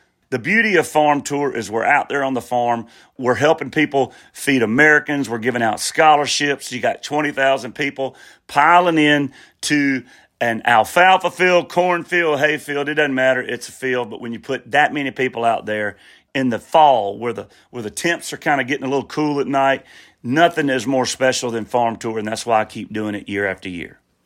Luke Bryan explains why he continues to do his Farm Tour year after year.